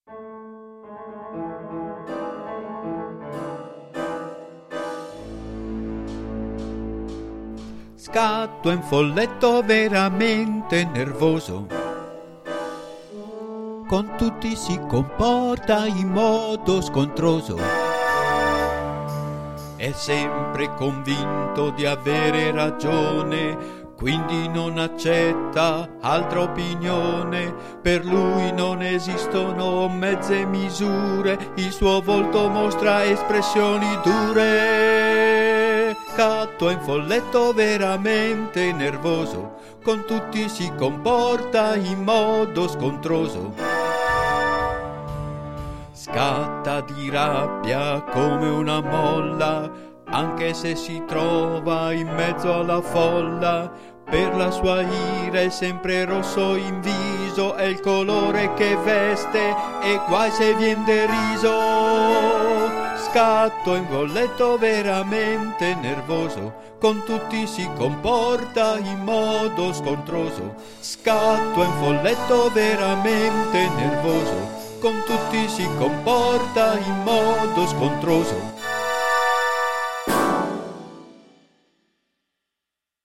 Canzoni dedicate ai sei Folletti scritte musicate e cantate